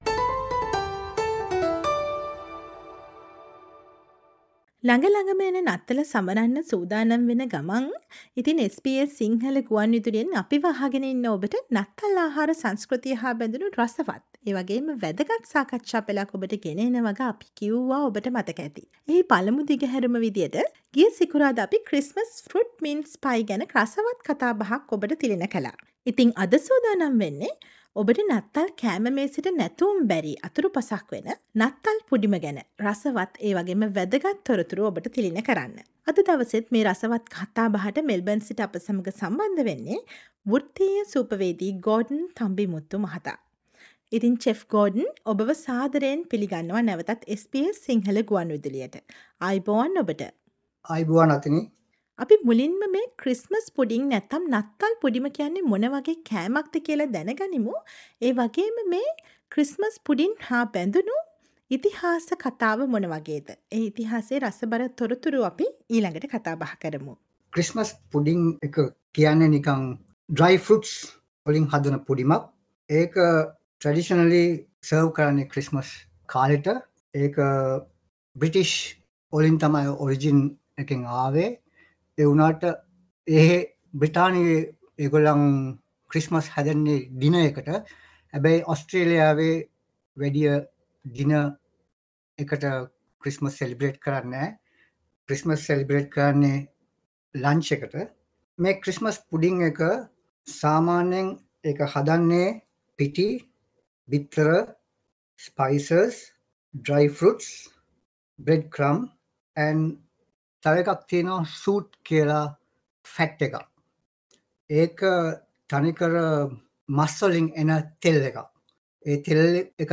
සම්ප්‍රදායික නත්තල් පුඩිම ඔස්ට්‍රේලියාවේදී Great Christmas pudding වුණ හැටි ගැන රසවත් කතාබහක්